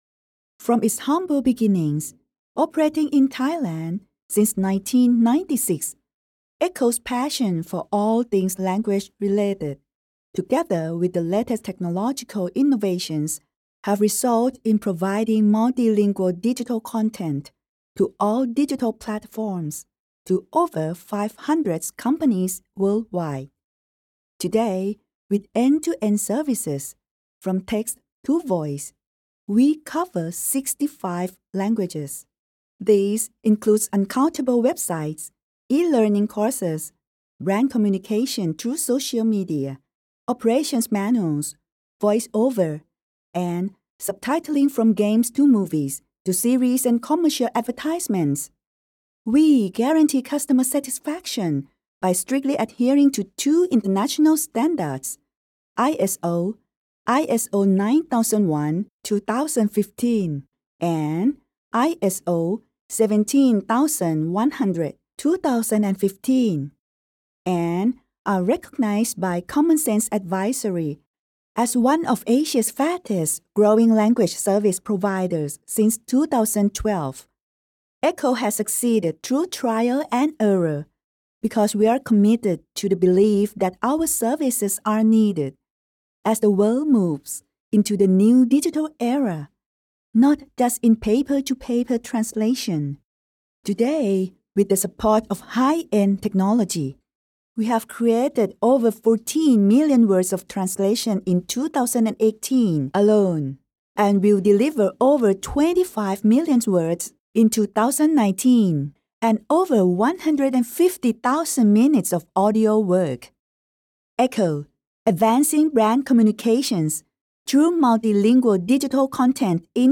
Voiceover Artists
English (Generic) Female 00110
NARRATION